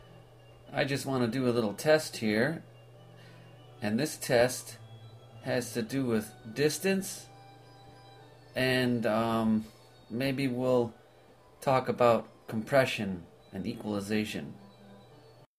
is about 14 or so inches from the mouth, voc 2 is six or so inches.  The difference,
present; voc 1 includes much more background, thus muddying the recording and
voc 1 (farther)
AUD_voice_far_NOcomp.mp3